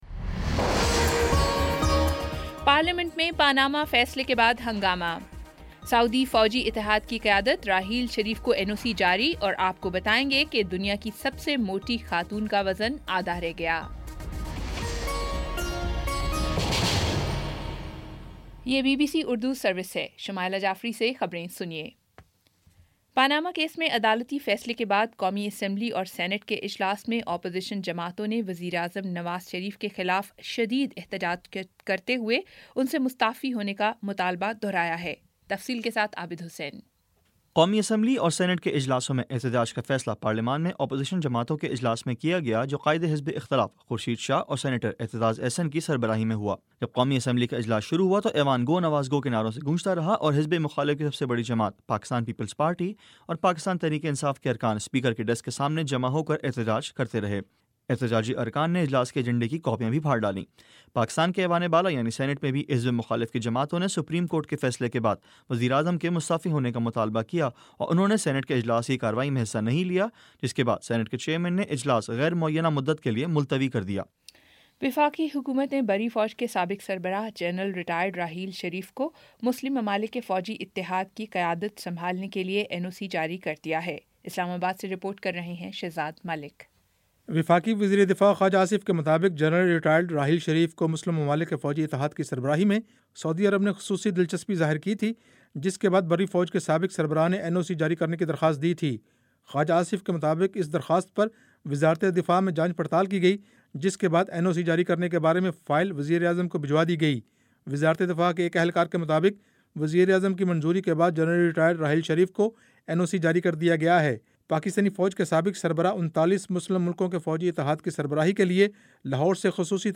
دس منٹ کا نیوز بُلیٹن روزانہ پاکستانی وقت کے مطابق شام 5 بجے، 6 بجے اور پھر 7 بجے